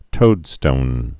(tōdstōn)